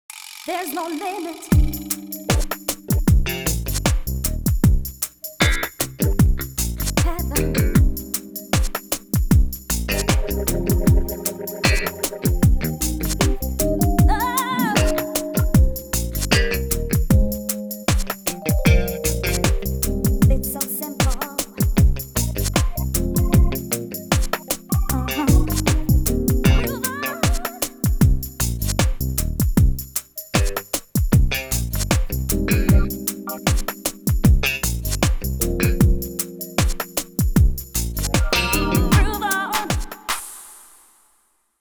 Soul funk 1 (bucle)
melodía
repetitivo
rítmico
sintetizador
soul